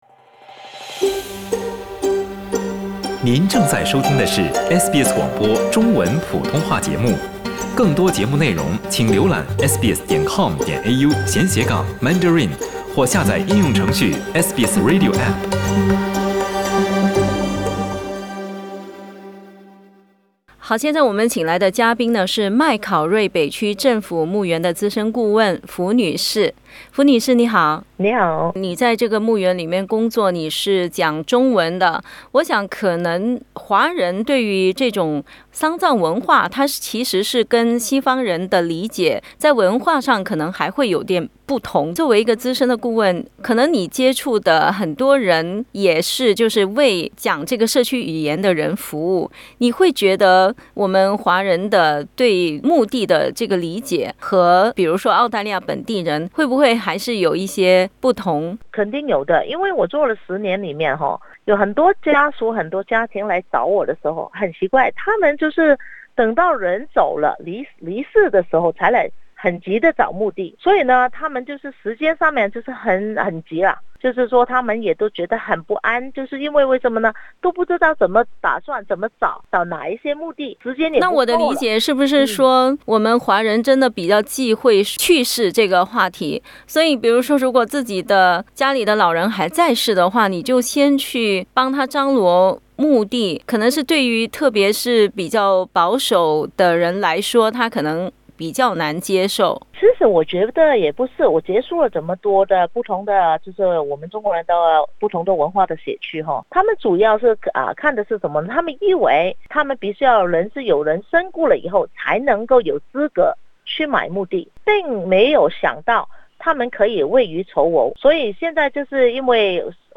（請聽寀訪） 澳大利亞人必鬚與他人保持至少1.5米的社交距離，請查看您所在州或領地的最新社交限制措施。